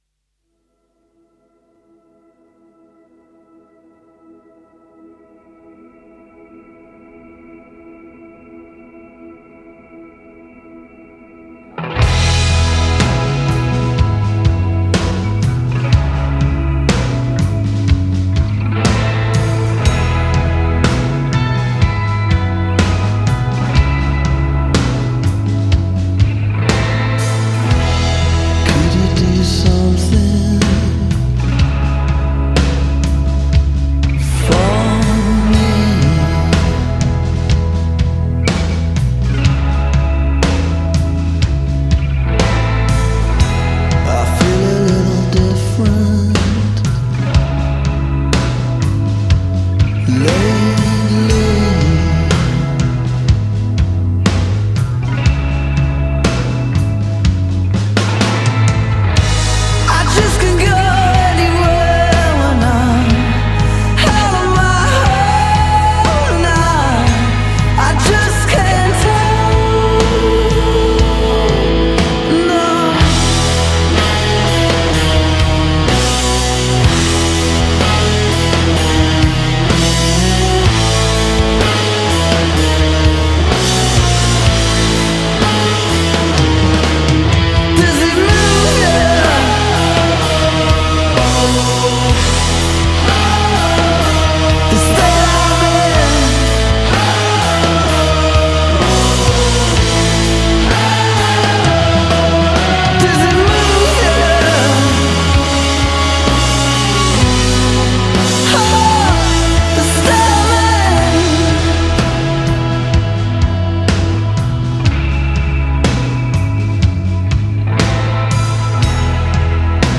it’s a grower with a Zeppelinesque heaviness.